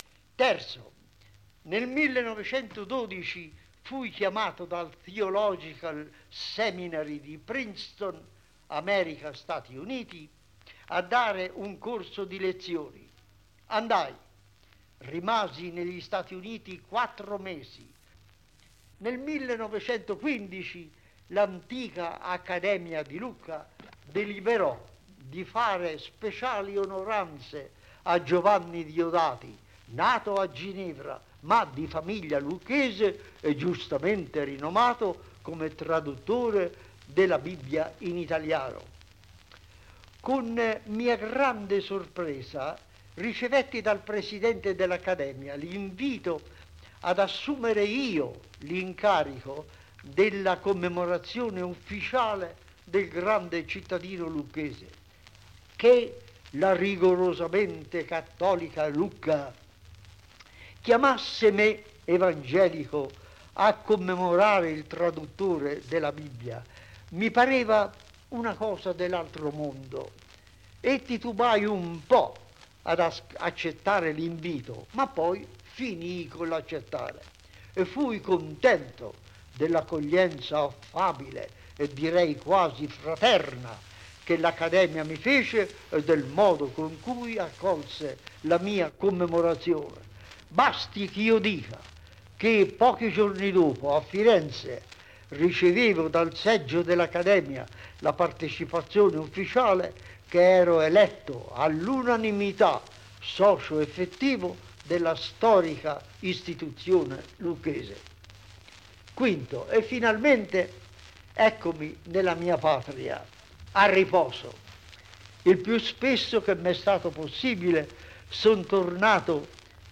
Intervista a Giovanni Luzzi